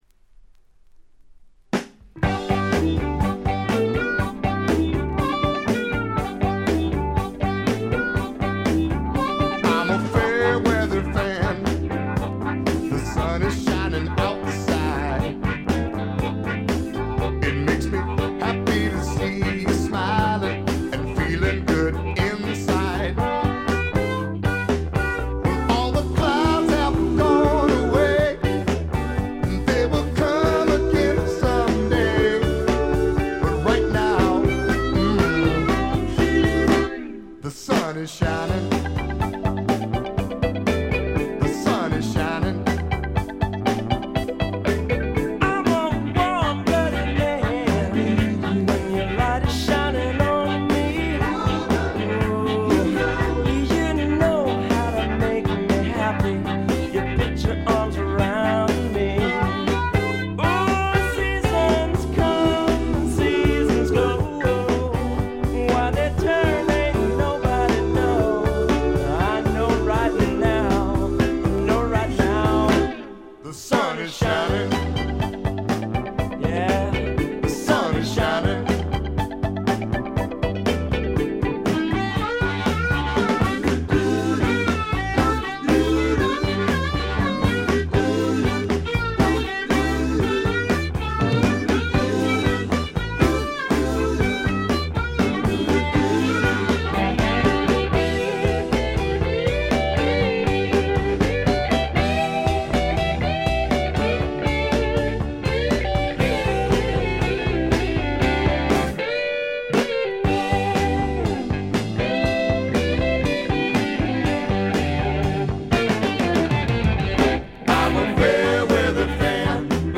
ほとんどノイズ感無し。
録音は英国ウェールズのおなじみロックフィールド・スタジオ。
ルーツ色を残した快作です。
試聴曲は現品からの取り込み音源です。